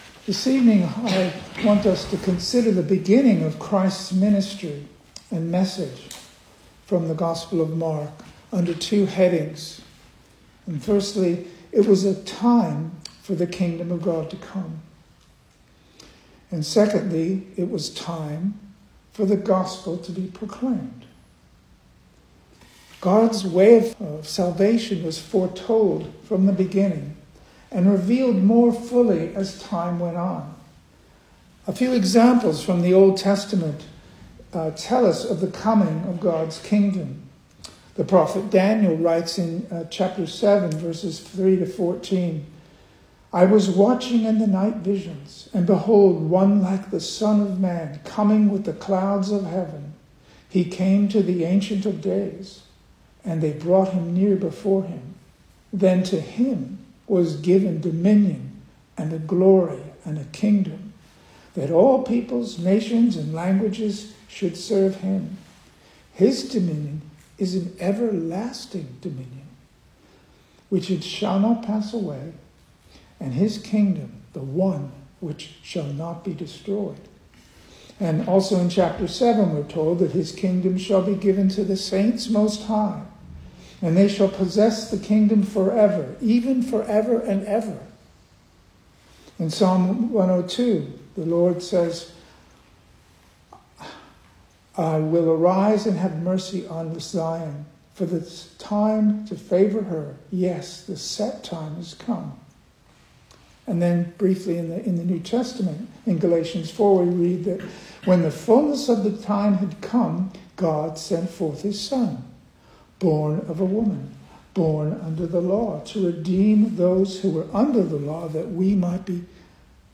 2025 Service Type: Weekday Evening Speaker
Single Sermons